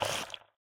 PixelPerfectionCE/assets/minecraft/sounds/mob/guardian/flop3.ogg at mc116
flop3.ogg